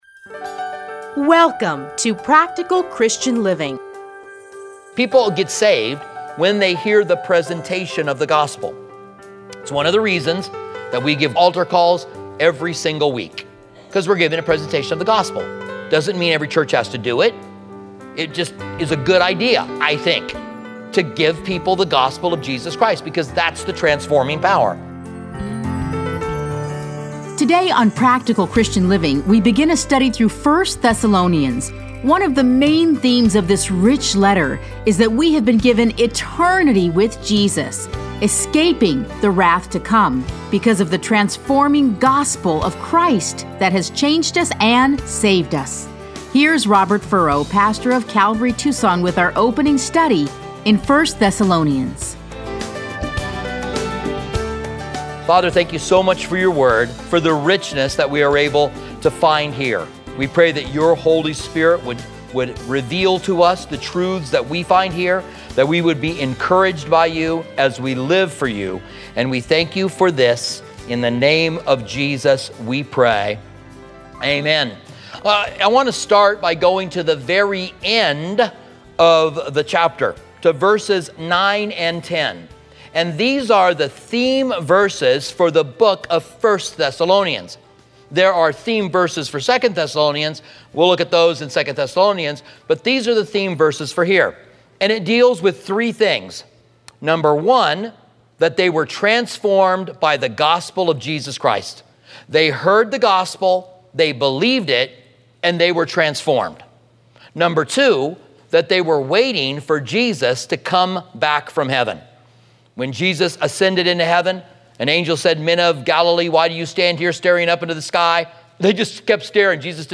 Listen to a teaching from 1 Thessalonians 1:1-10.